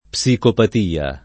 psicopatia [ p S ikopat & a ]